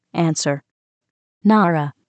◇音声は日本語、英語ともに高音質のスピーチエンジンを組み込んだ音声ソフトを使って編集してあります。
音声−答え